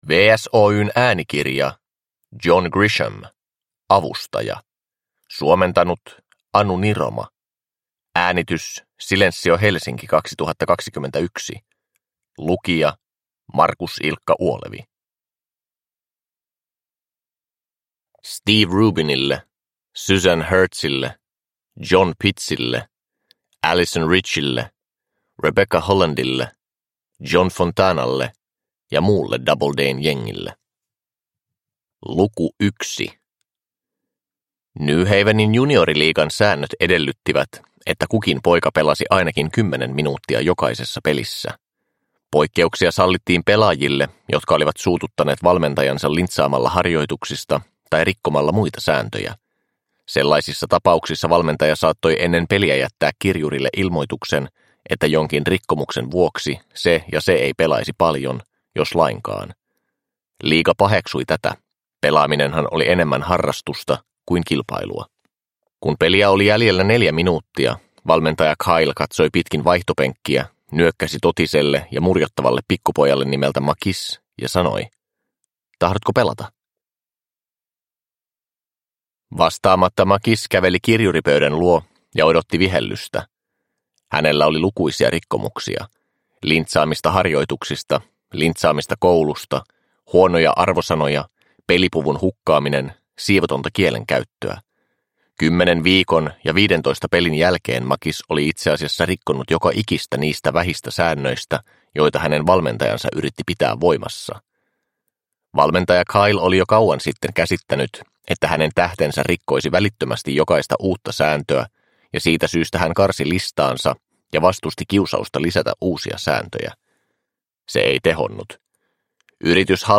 Avustaja – Ljudbok – Laddas ner